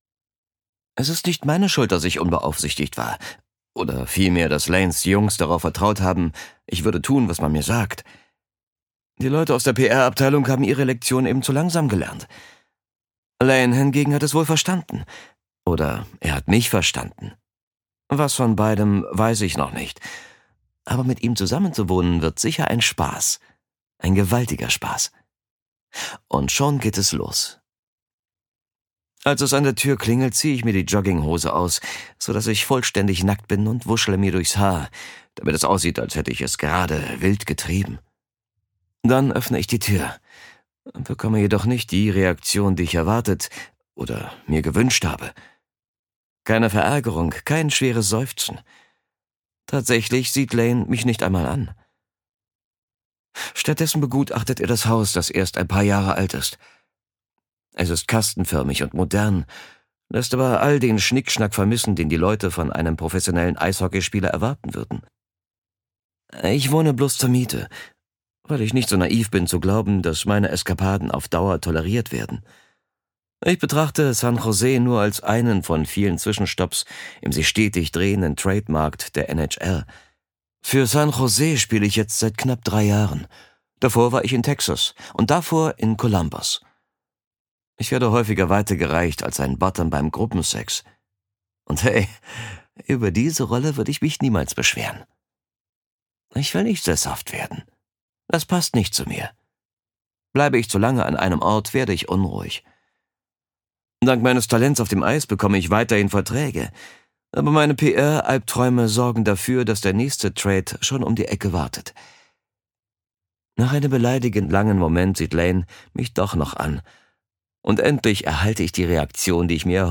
Seine junge, tiefe Stimme macht ...